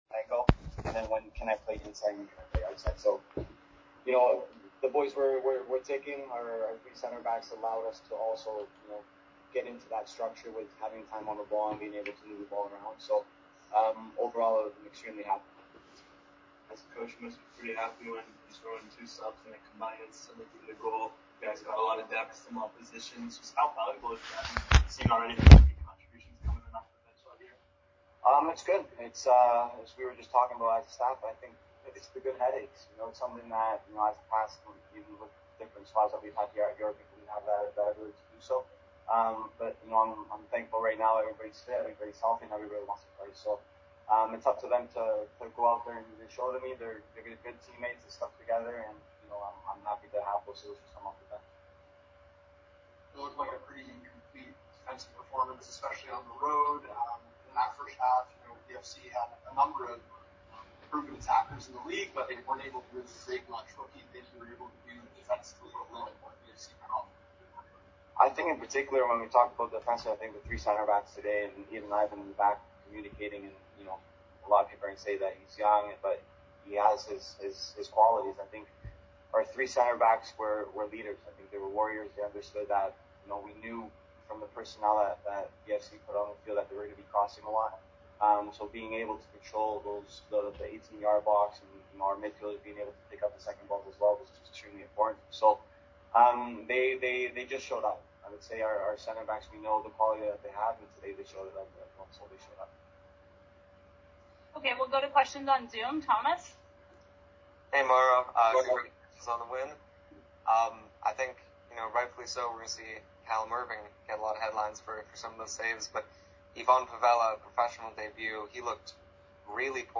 April 6, 2025...Post Game press conference audio after the CPL game between Vancouver FC vs York United FC
at Langley Events Centre the Township of Langley BC.
(joined in progress)